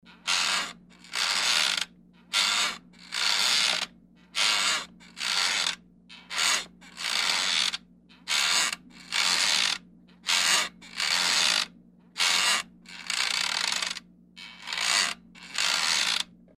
Кресло качалка слегка скрипит